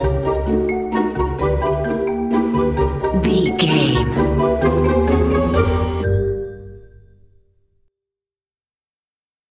Uplifting
Ionian/Major
D
Slow
flute
oboe
strings
cello
double bass
percussion
sleigh bells
silly
comical
cheerful
quirky